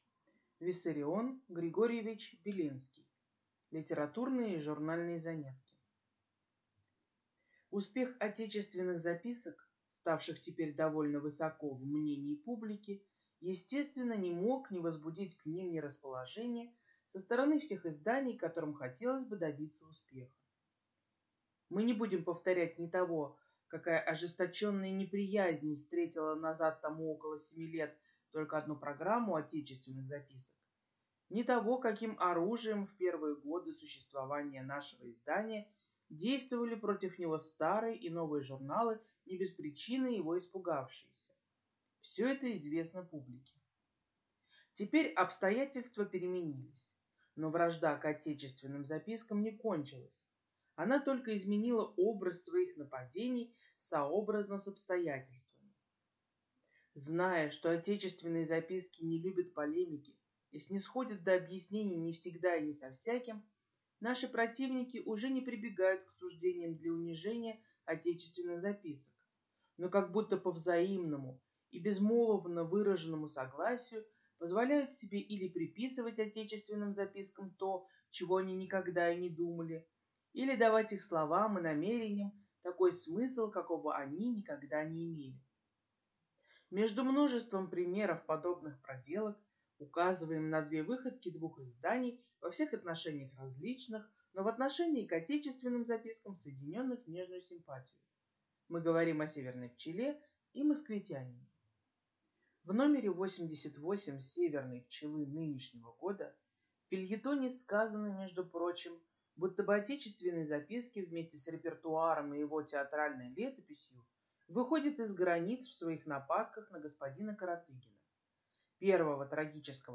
Аудиокнига Литературные и журнальные заметки | Библиотека аудиокниг